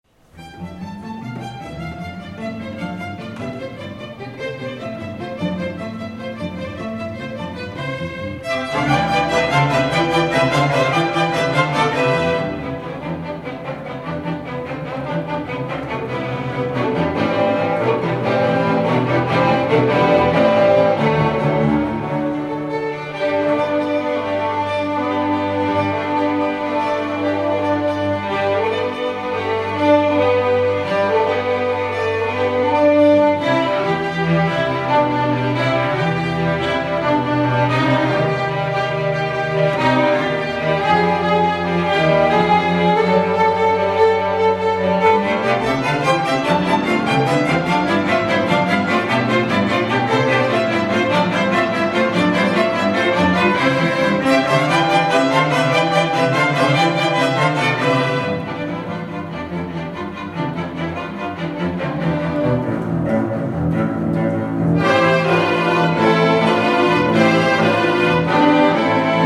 F. Farkaš Piccola musica, 4. věta allegro 1:09 1 385 KB